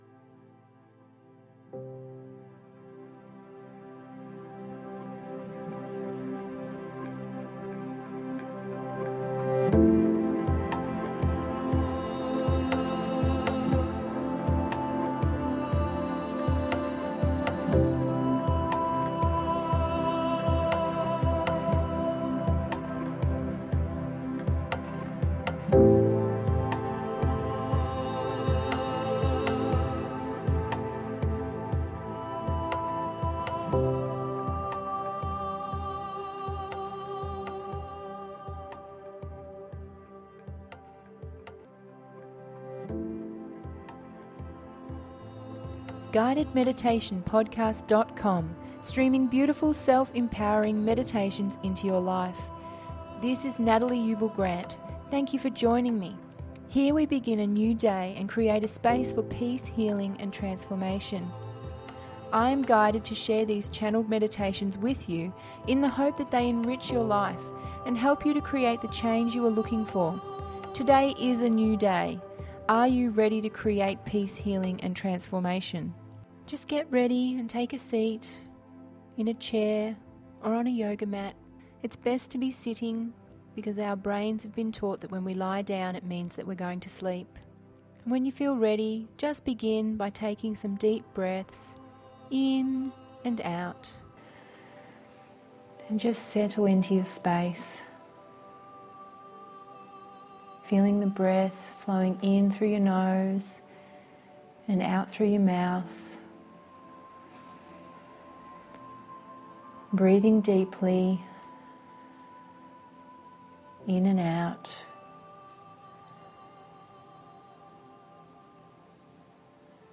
The Great Lake Temple…029 – GUIDED MEDITATION PODCAST